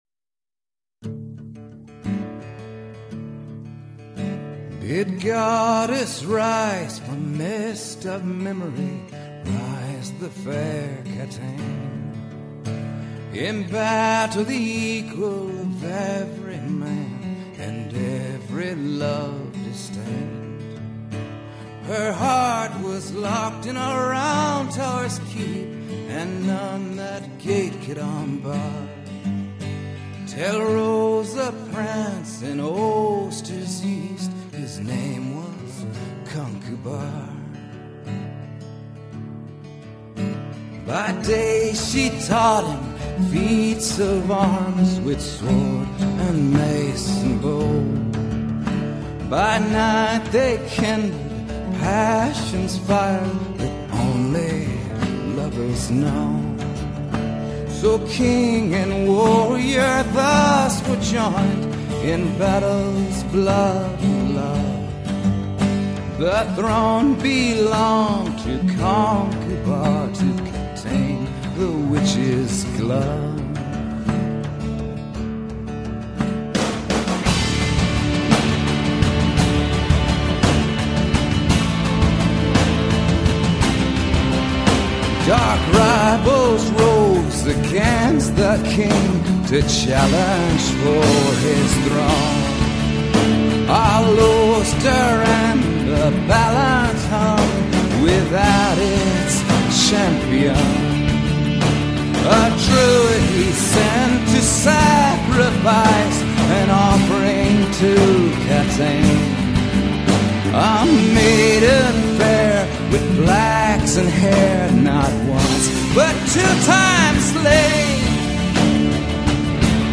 Anywhooo... it's a great cool-down/stretch tune.